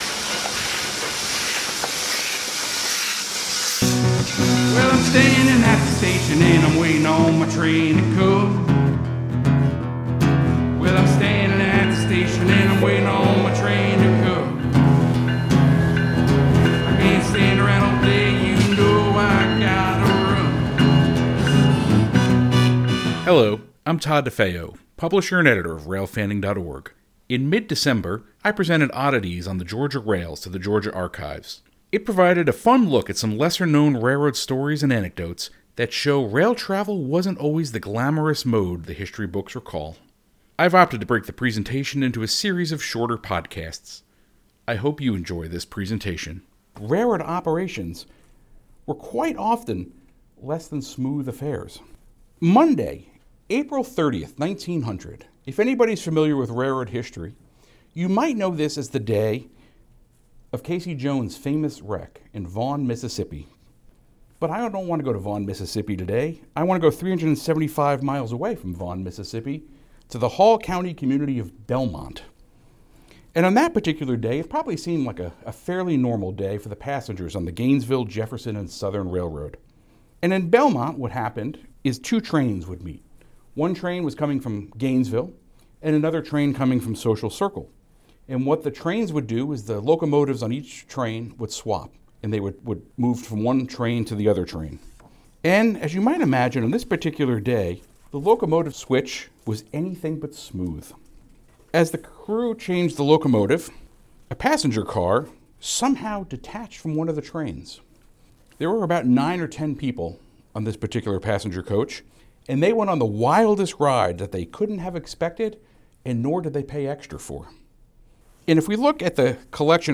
It provided a fun look at some lesser-known railroad stories and anecdotes that show rail travel wasn’t always the glamorous mode the history books recall. This is the second episode in a series of podcasts based on that presentation.
• This presentation snippet has been edited.